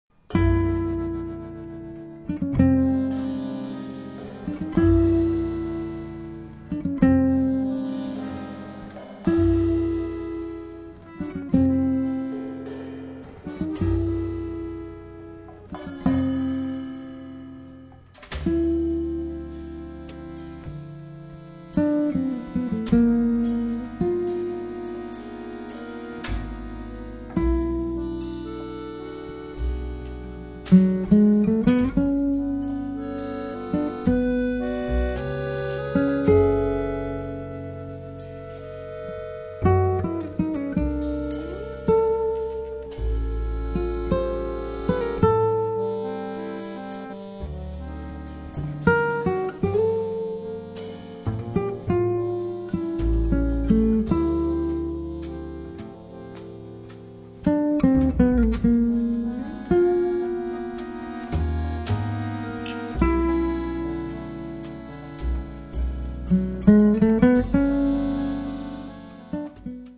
chitarra
fisarmonica
contrabbasso
batteria